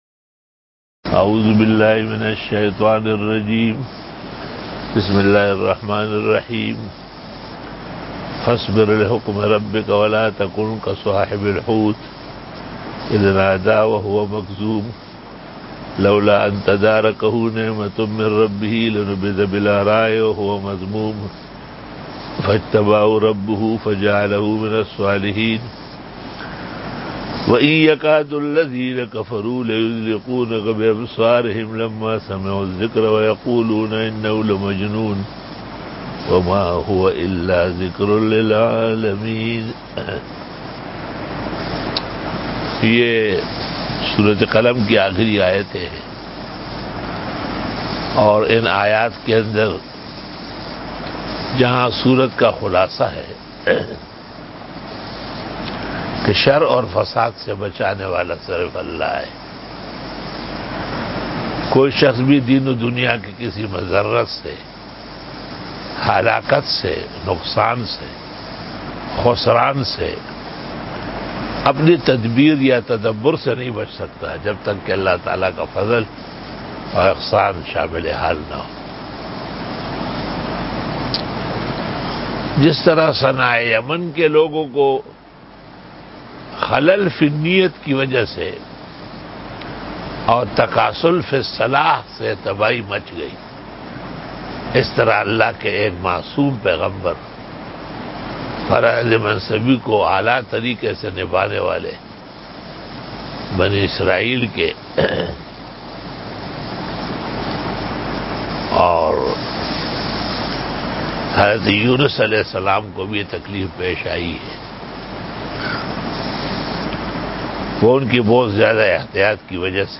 85 Quran Tafseer 12 July 2020 ( 20 Zil Qaadah 1441 H) - Sunday Day 85